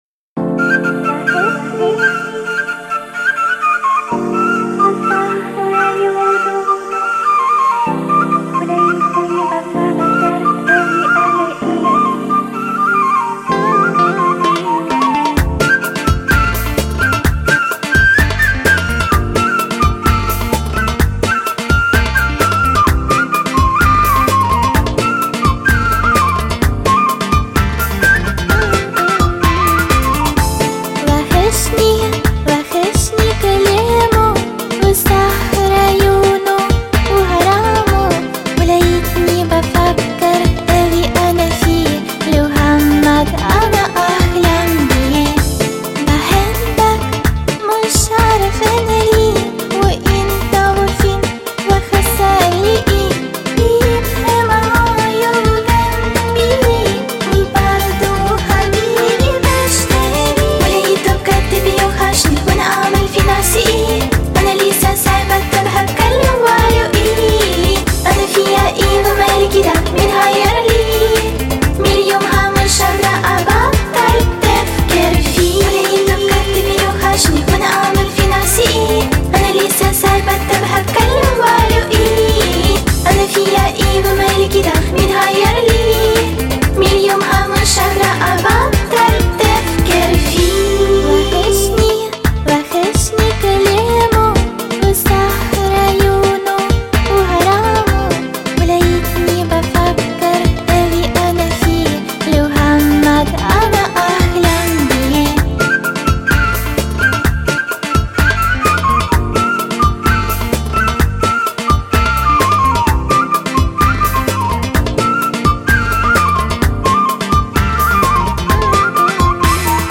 Сопрано